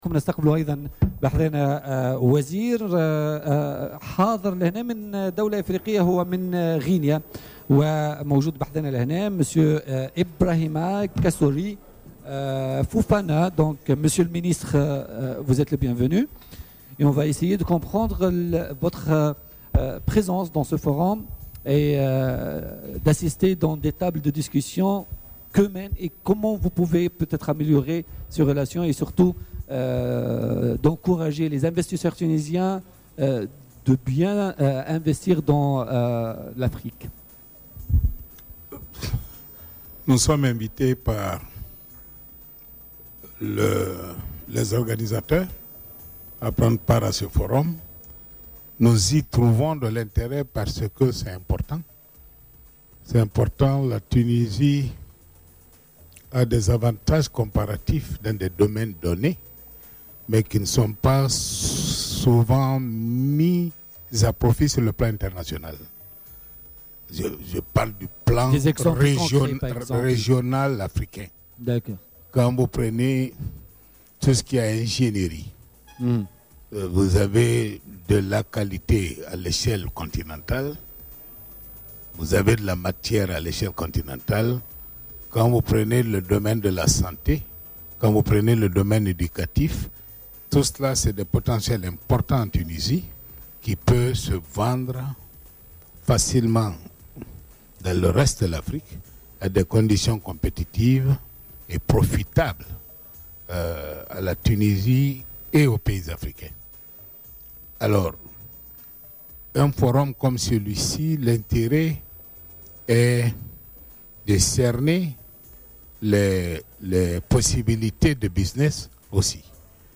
وشدّد الوزير خلال استضافته اليوم في حلقة خاصة من "بوليتيكا" بمناسبة حضوره أشغال الملتقى التونسي الإفريقي الذي ينعقد اليوم وغدا في تونس، على ضرورة تقوية العلاقة بين تونس وجميع بلدان إفريقيا، خاصة على الصعيد الاقتصادي والمبادلات الاقتصادية والاستثمار.